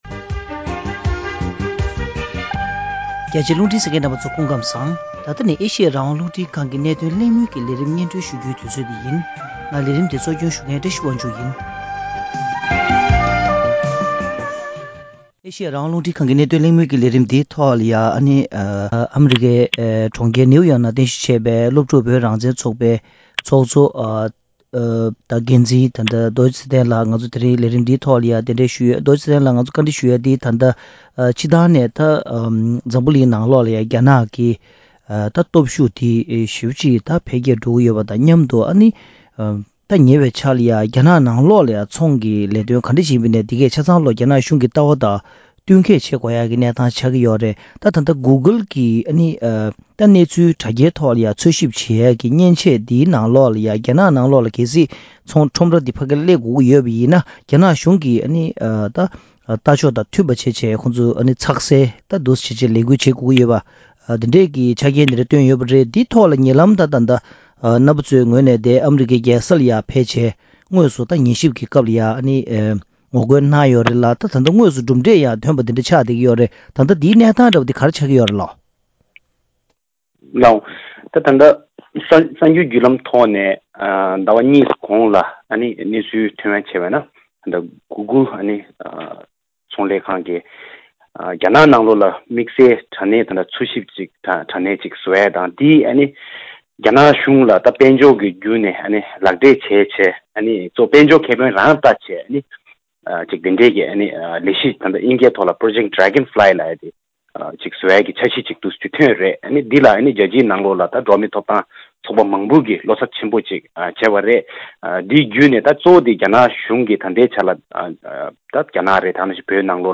༄༅། །གནད་དོན་གླེང་མོལ་གྱི་ལས་རིམ་ནང་།